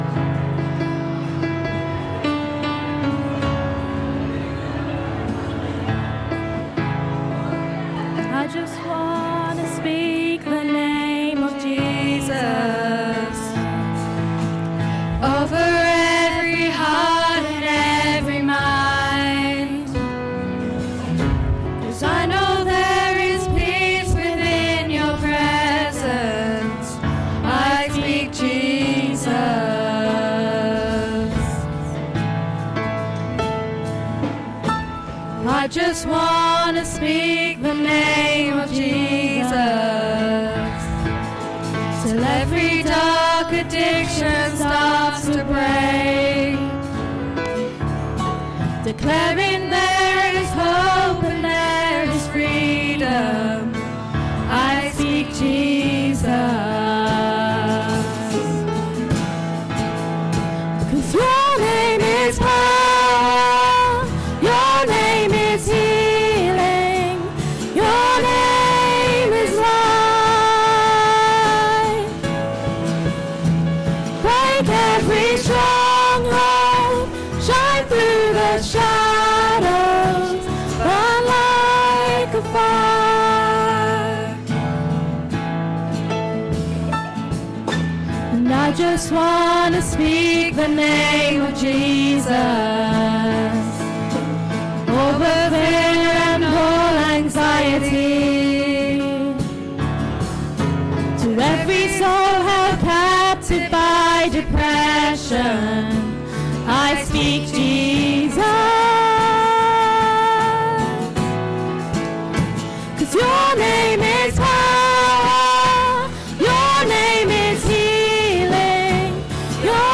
Youth service - Sittingbourne Baptist Church
Join us for a service led by the youth of the church.